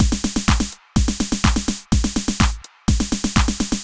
Beat 06 Full (125BPM).wav